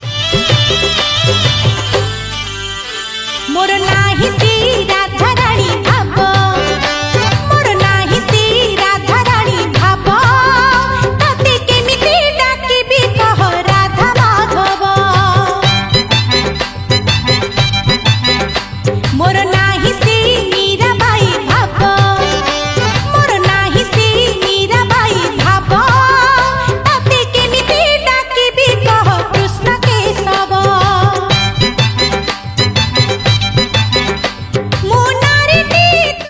Odia Bhajan Ringtones